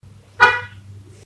horn.ogg